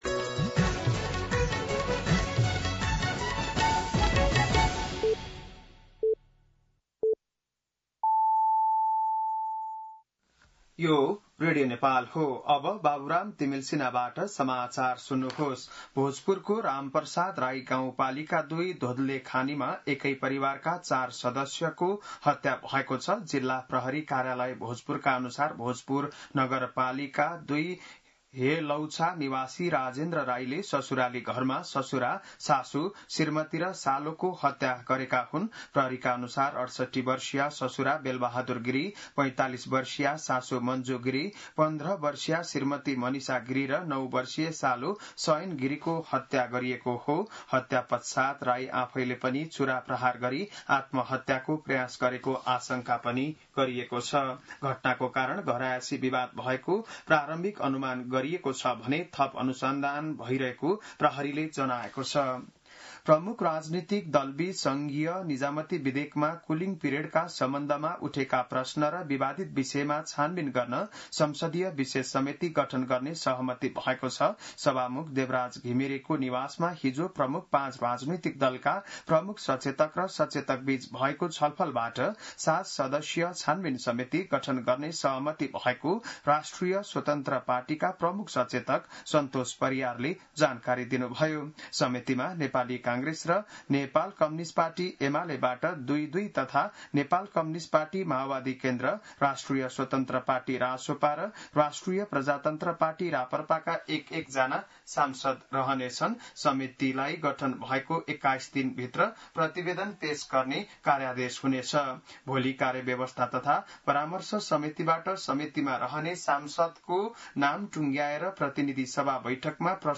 बिहान ११ बजेको नेपाली समाचार : २२ असार , २०८२